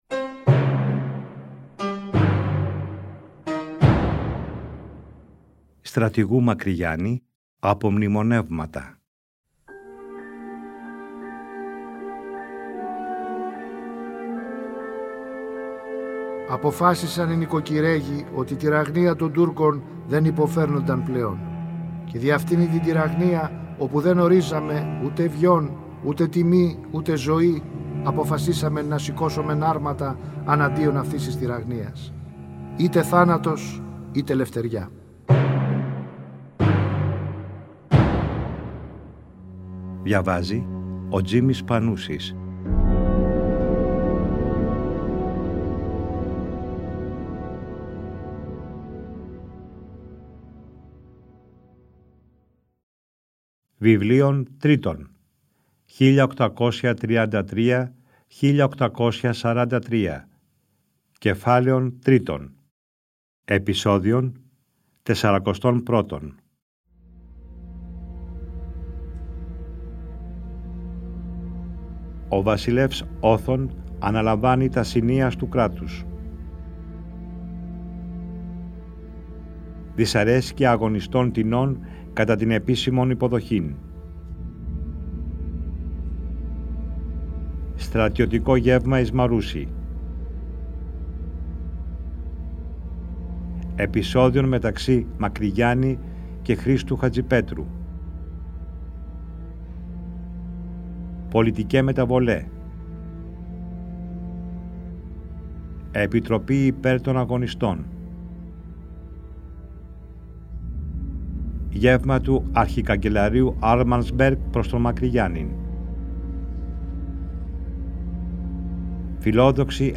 Τον Ιούνιο του 2012 το Τρίτο Πρόγραμμα παρουσίασε για πρώτη φορά μια σειρά 60 ημίωρων επεισοδίων, με τον Τζίμη Πανούση να διαβάζει τον γραπτό λόγο του Μακρυγιάννη, όπως ο ίδιος ο Στρατηγός τον αποτύπωσε στα “Απομνημονεύματα” του. Το ERT εcho σε συνεργασία με το Τρίτο Πρόγραμμα αποκατέστησαν ψηφιακά τα αρχεία.
Ανάγνωση: Τζίμης Πανούσης